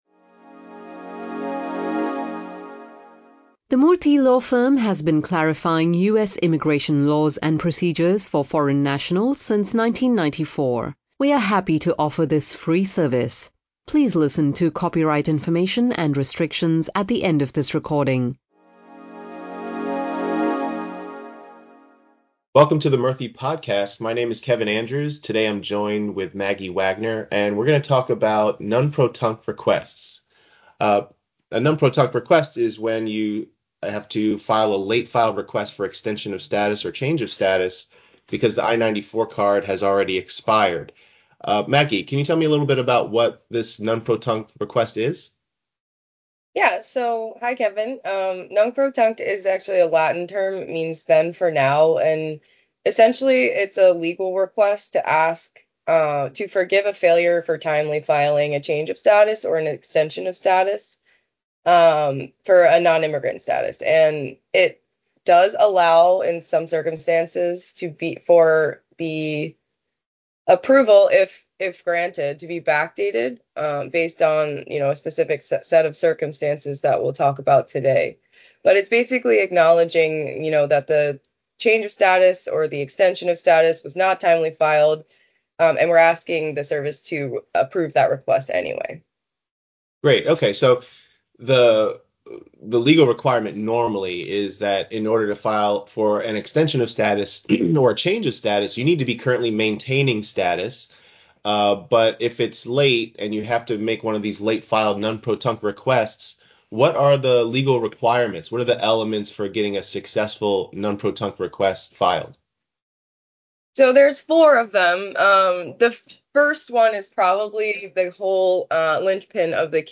Late-filed petitions and applications and nunc pro tunc relief are topics discussed for you by Murthy Law Firm attorneys in this podcast.